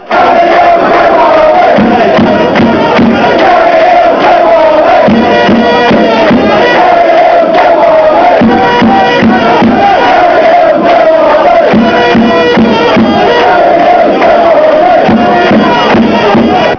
Chants from the match against Orient
Quality is pretty rough but if you were there they will probably put a smile on your face, if you weren't you will probably wish you were!